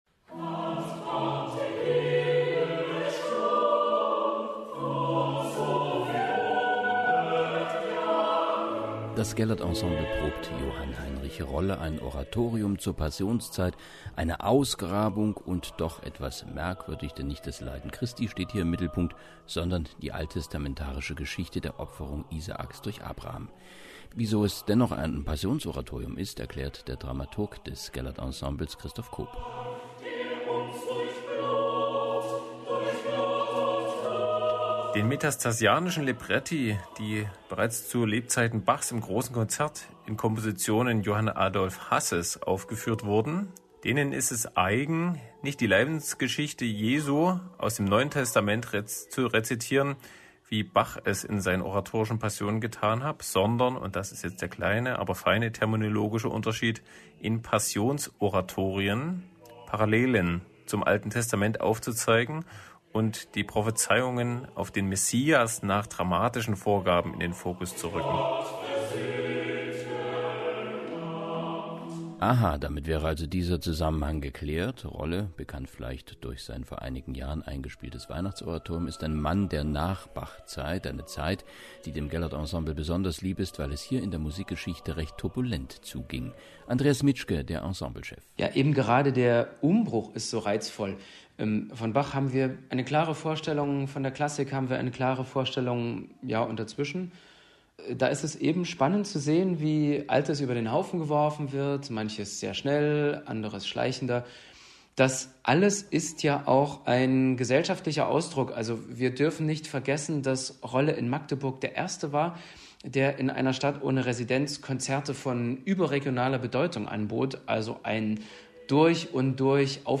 Porträt: Gellert Ensemble